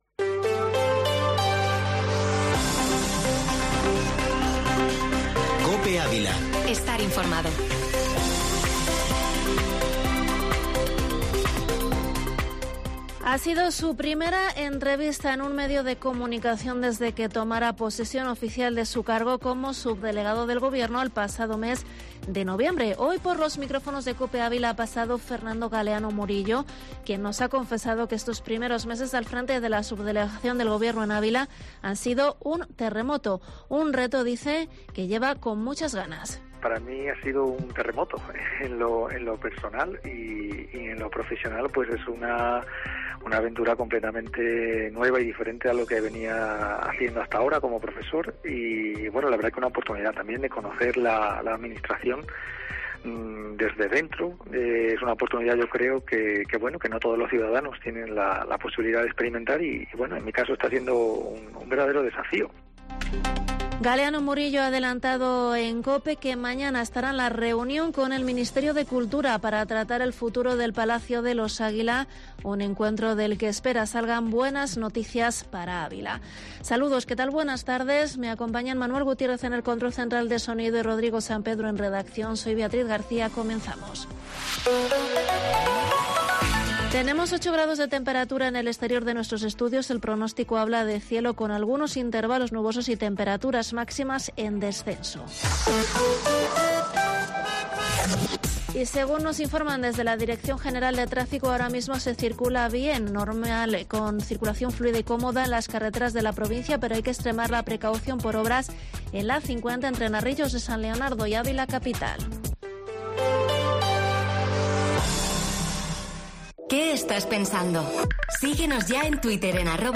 Informativo Mediodía Cope en Avila 31/1/22